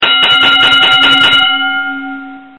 Dzwonki na telefon Boxing Bell Sms